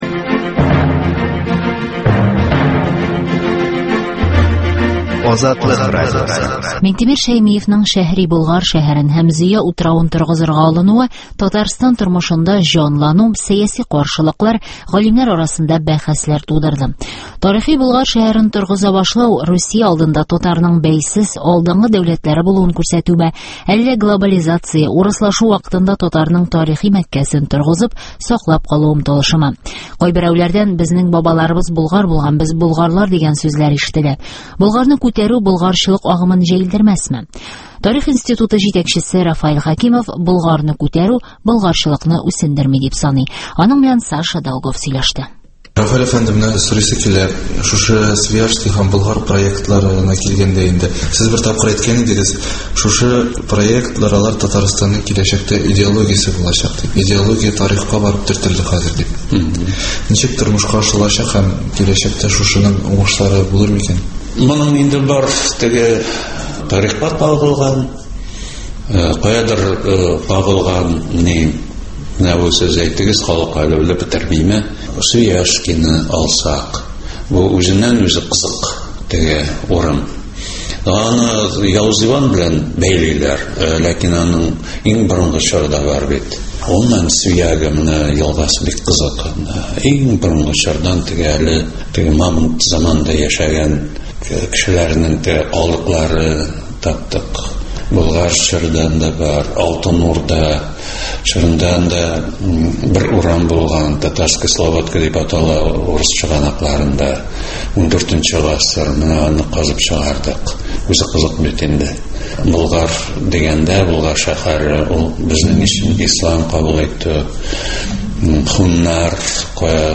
Рафаил Хәкимов белән әңгәмә